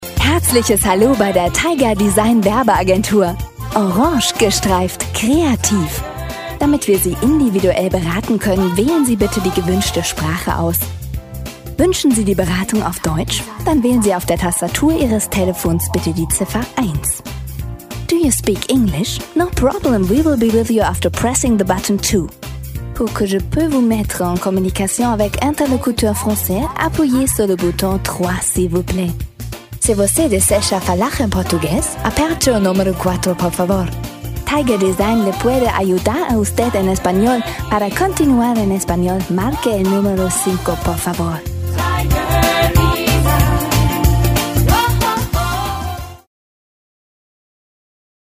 Sprechprobe: Sonstiges (Muttersprache):
german female voice over artist.